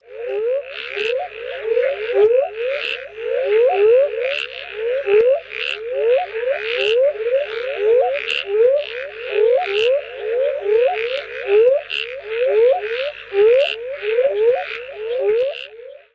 Northern Spadefoot Toad, Golfball Frog